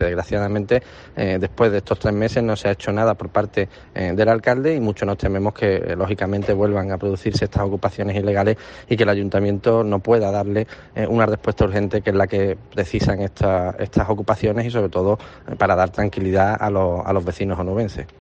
Guillermo García de Longoria, portavoz C's en el Ayuntameinto